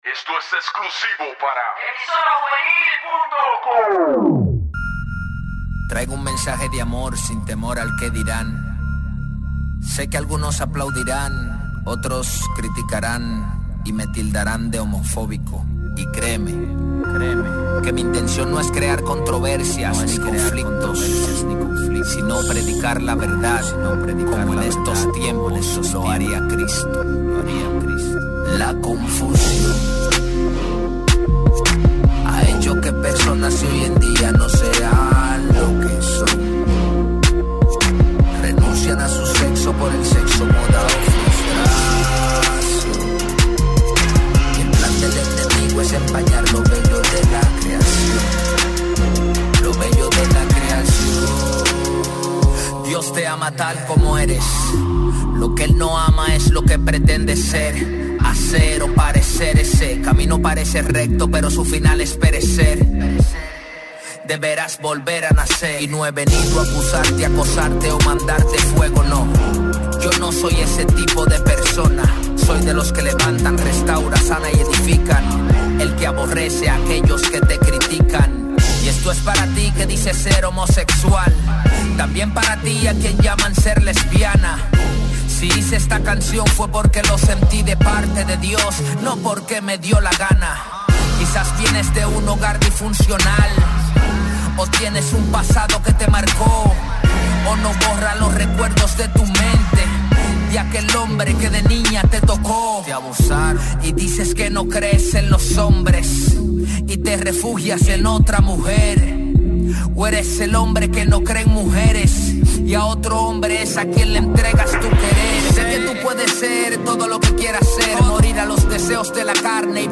Musica Cristiana
música urbana